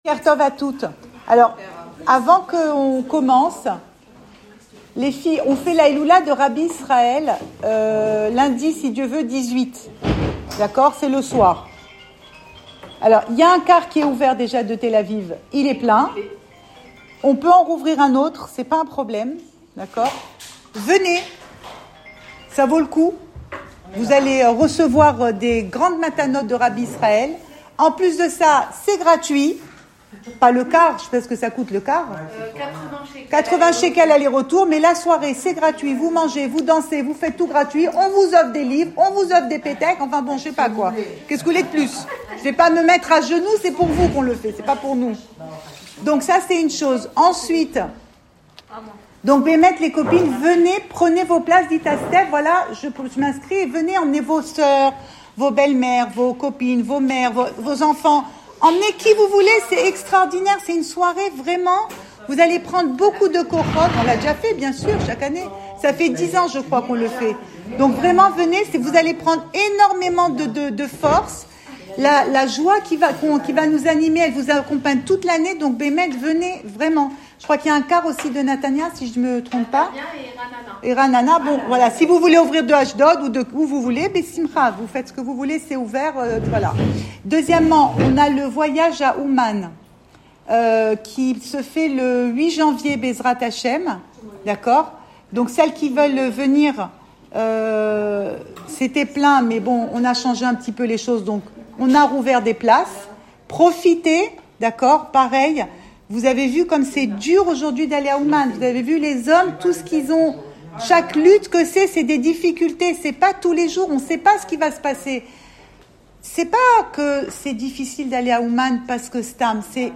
Cours audio Emouna Le coin des femmes Pensée Breslev - 13 novembre 2024 1 mai 2025 Chacun sa life. Enregistré à Tel Aviv